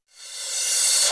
电子逆向碰撞 (2)
描述：数字反转镲片
Tag: 鼓数字化 碰撞转